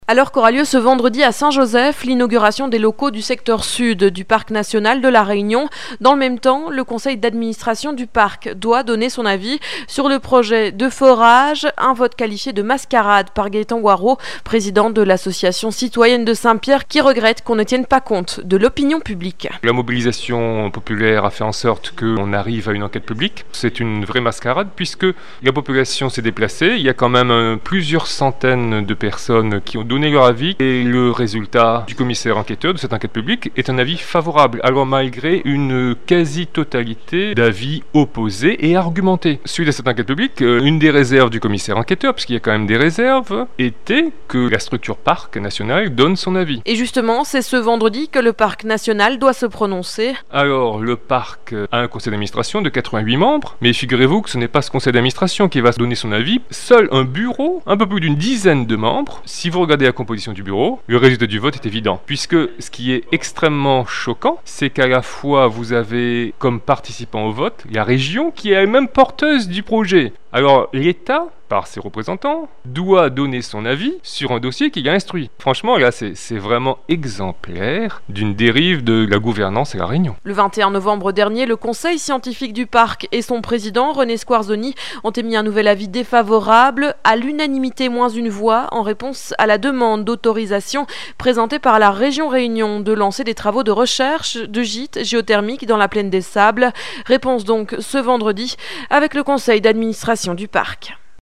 2008_12_04_Interview_par_Radio_Festival.mp3